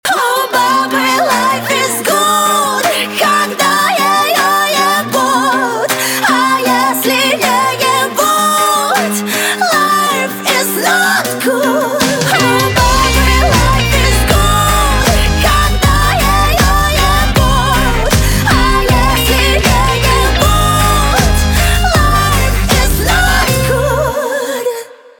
русский рок , хлопки , гитара , барабаны